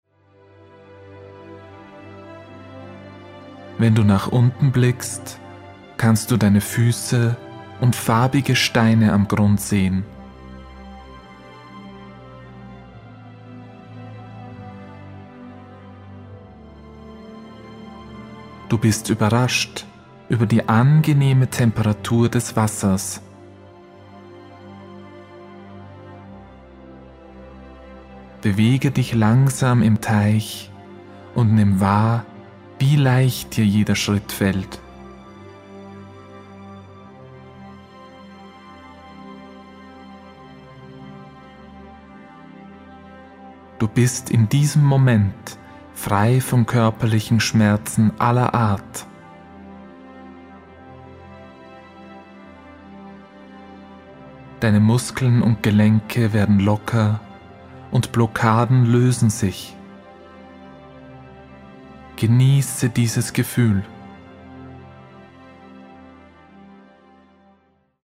Autogenes Training
Entspanung für Senioren
Inhalt: Die CD enthält ein Komplettprogramm Autogenes Training, Übungen aus der Progressiven Muskelentspannung nach Jacobson und zwei Meditationen (Spaziergang zur Quelle der Jugend und Frühjahrsputz).